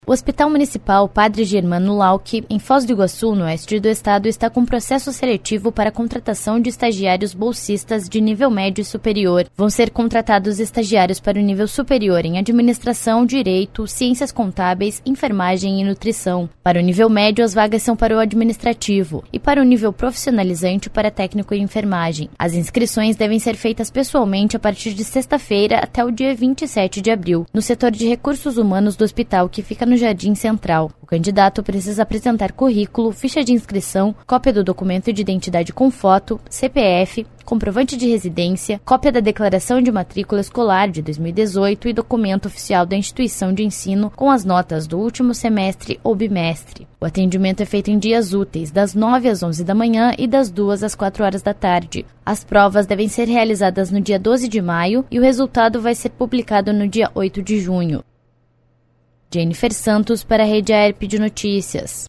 18.04 - BOLETIM SEM TRILHA - Hospital Municipal de Foz do Iguaçu abre processo seletivo para estagiários